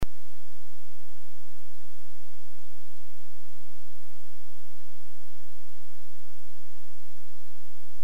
The test was done outside the casing with unidirectional microphone pointing directly to the fan.